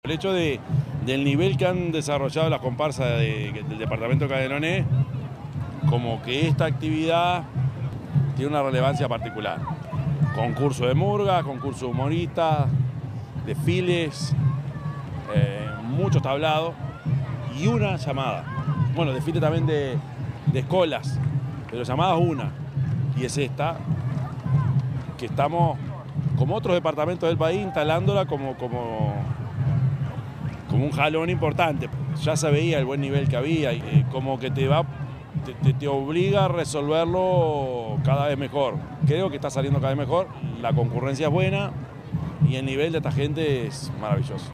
intendente_yamandu_orsi_2.mp3